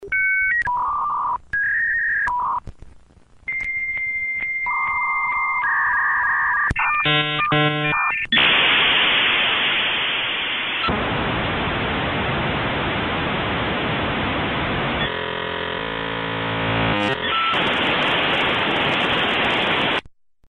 На этой странице собраны звуки, передающие атмосферу размышлений: задумчивые паузы, едва уловимые вздохи, фоновое бормотание.
Звуковой эффект зависания человека